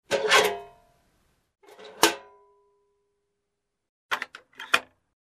Звук открывания и закрывания почтового металлического ящика